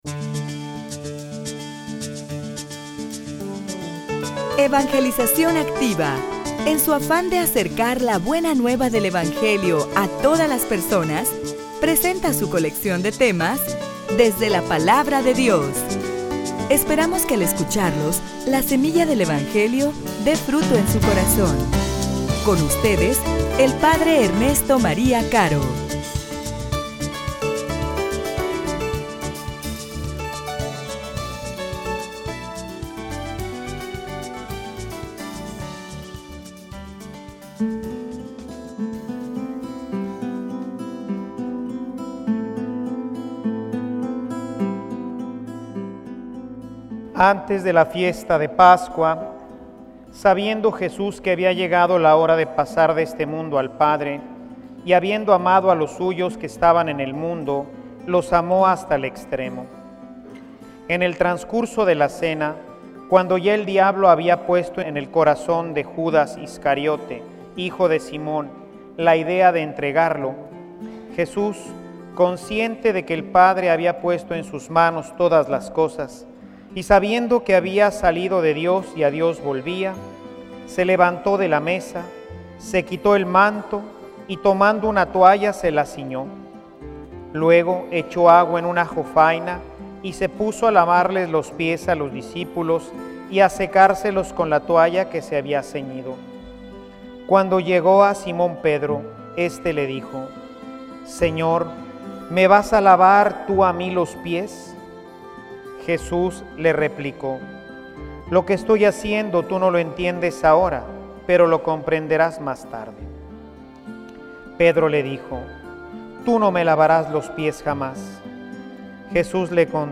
homilia_La_Alianza_entre_Dios_y_el_hombre.mp3